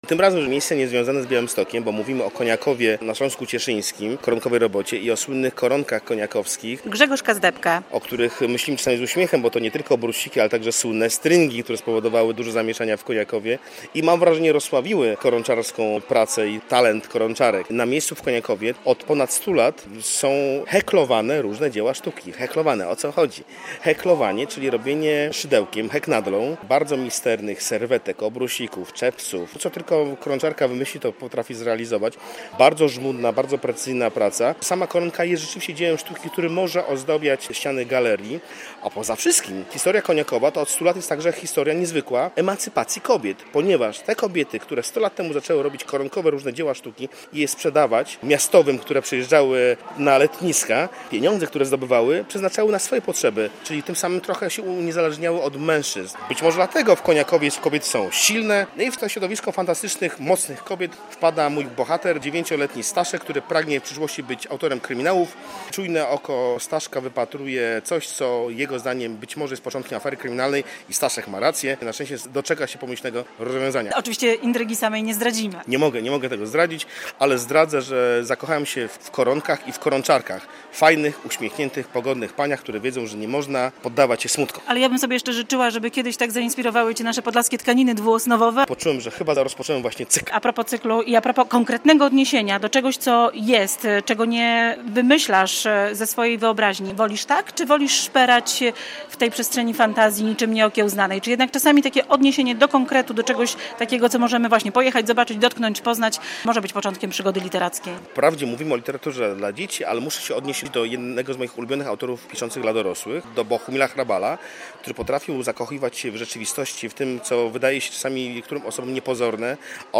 9. Targi Książki i festiwal "Na pograniczu kultur" w Białymstoku